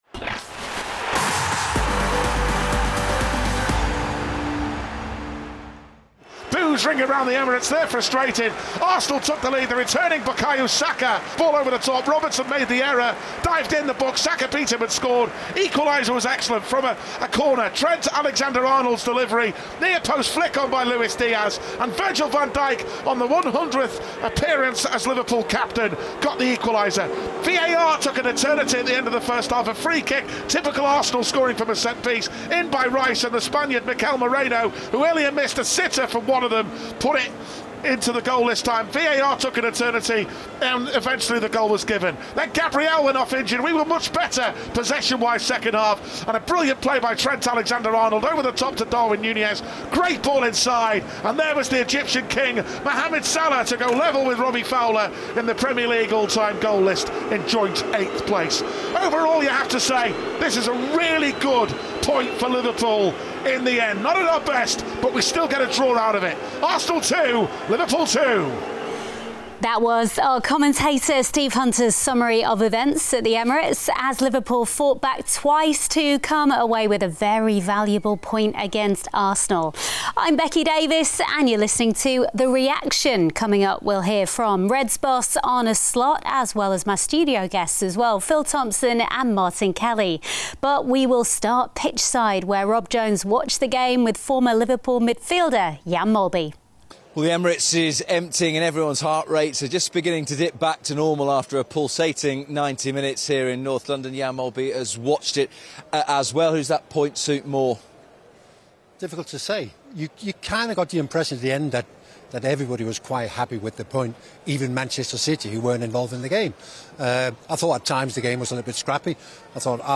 Mo Salah's 81st minute leveller earned Liverpool a valuable point at Arsenal on Sunday night, and in this episode of The Reaction podcast we'll hear from a proud Arne Slot who was delighted with the battling qualities on show. We also have the thoughts of former Liverpool players Jan Molby, Phil Thompson and Martin Kelly.